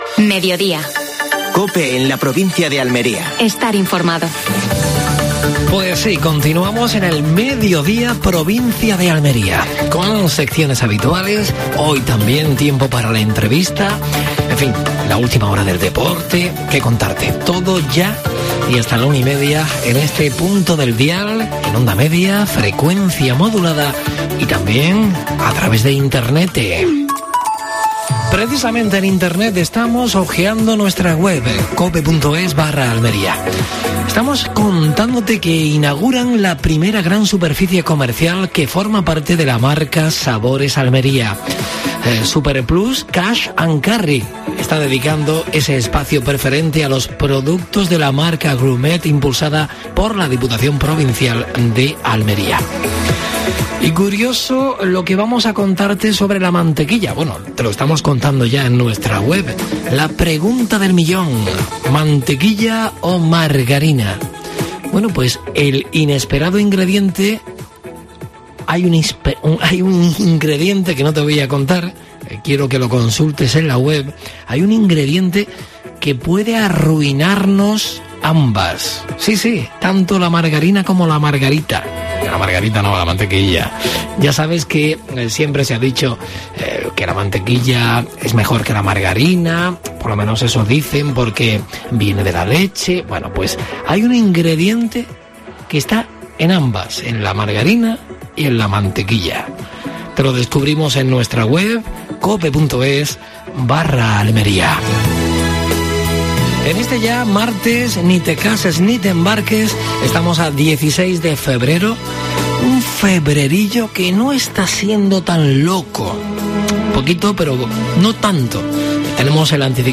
AUDIO: Actualidad en Almería. Entrevista a Ismael Torres (alcalde de Huércal de Almería). Última hora deportiva.